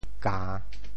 酵 部首拼音 部首 酉 总笔划 14 部外笔划 7 普通话 jiào 潮州发音 潮州 hao1 文 gan3 白 潮阳 hao1 文 gan3 白 澄海 hao1 文 gan3 白 揭阳 hao1 文 gan3 白 饶平 hao1 文 gan3 白 汕头 hao1 文 gan3 白 中文解释 酵〈名〉 (形声。